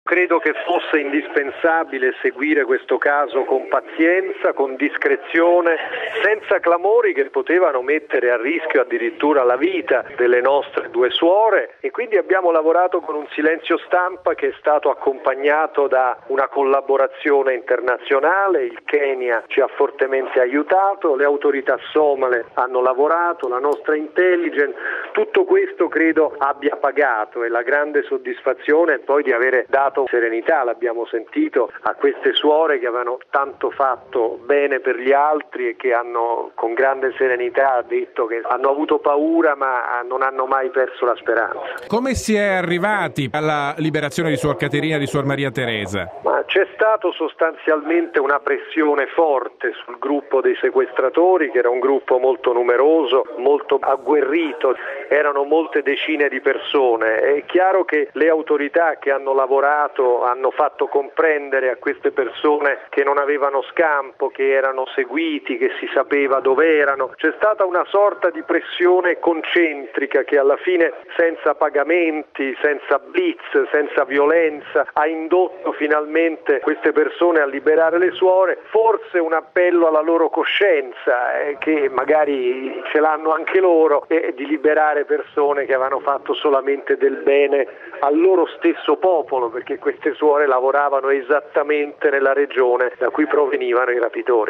Presto in Italia le due suore rilasciate in Somalia. Intervista con il ministro Frattini